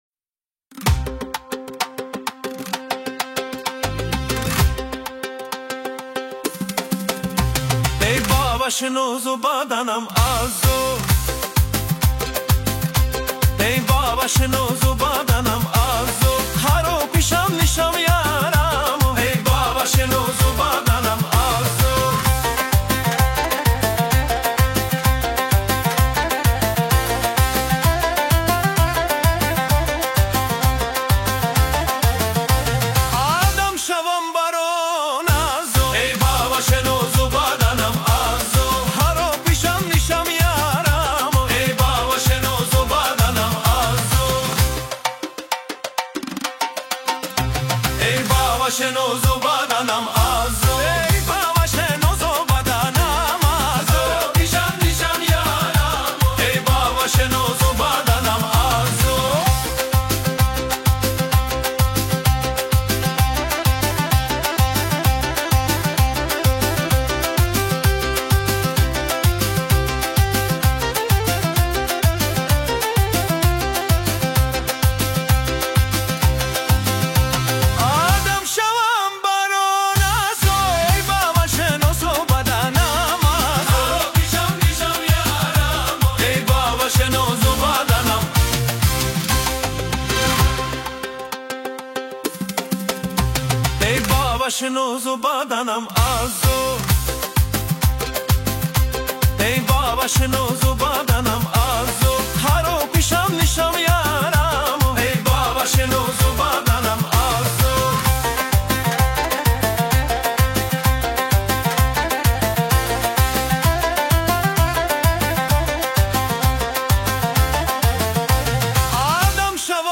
ДИНАМИЧНАЯ МУЗЫКА